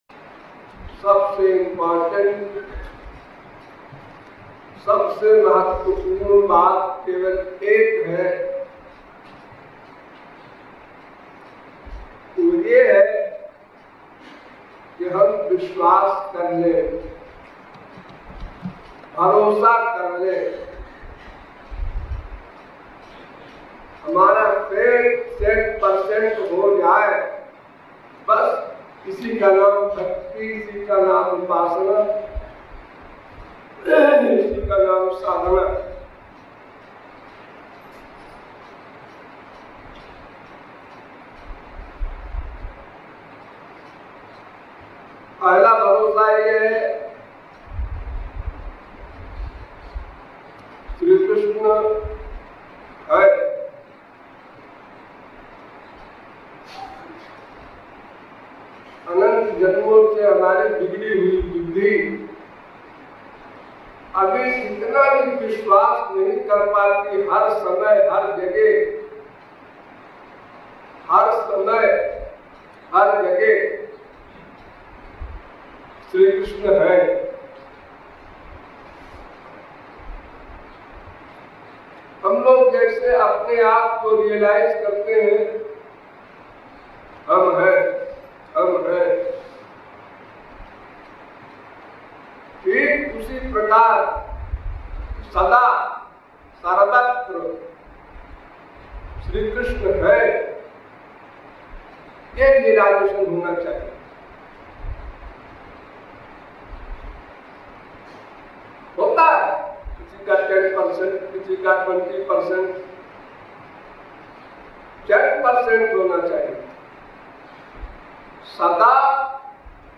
In this special podcast, you can listen to the very important lecture